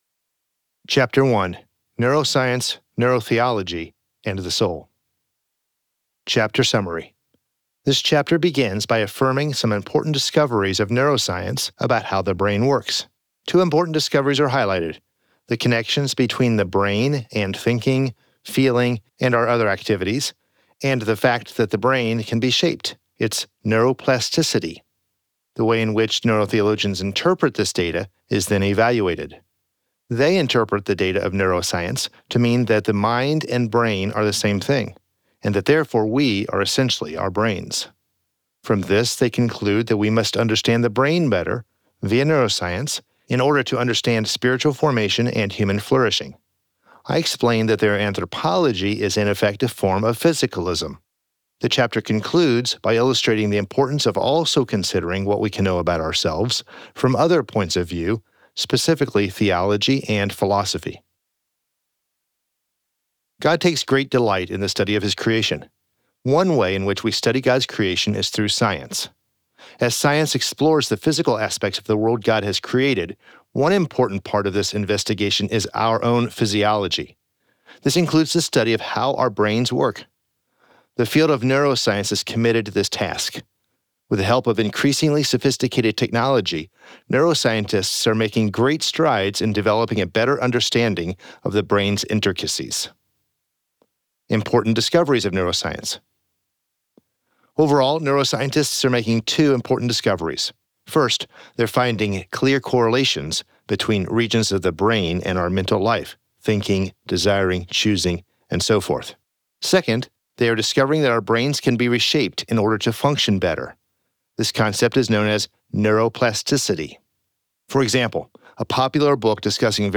Audio Book Sample